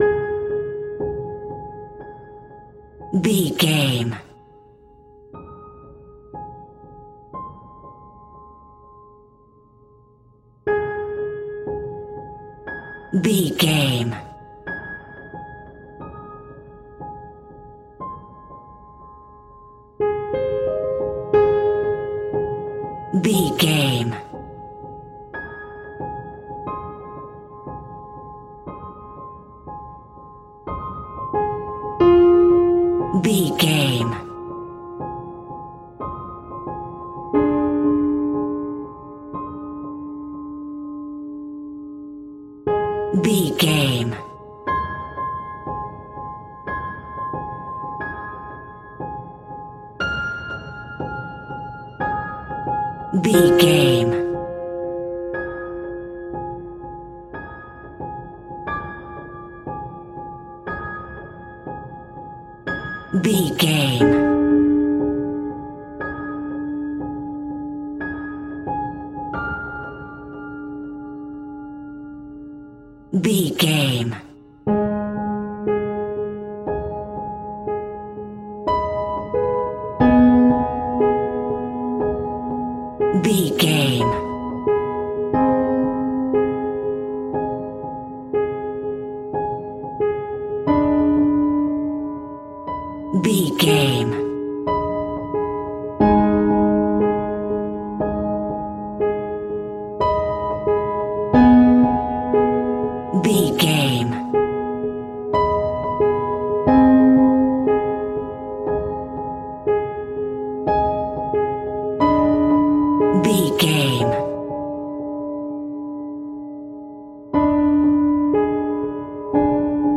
Piano Haunting Solo.
Ionian/Major
A♭
scary
ominous
haunting
eerie
horror piano